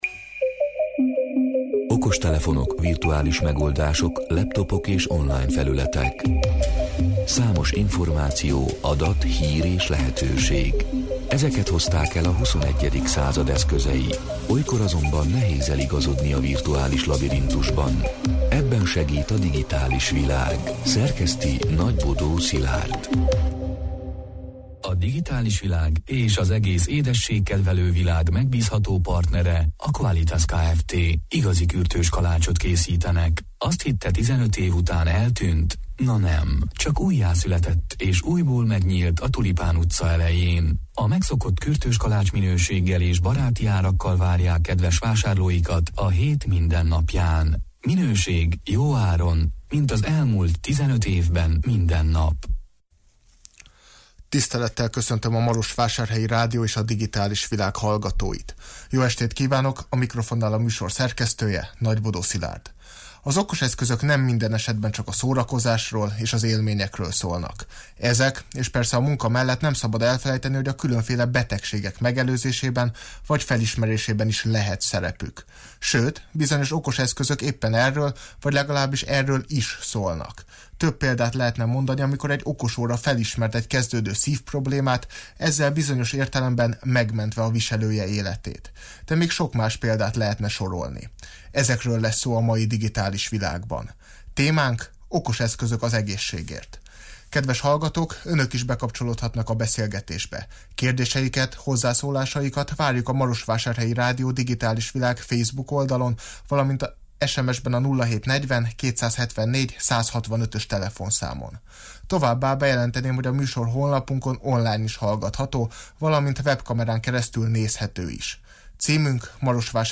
(elhangzott: 2021. szeptember 28-án, 20 órától élőben)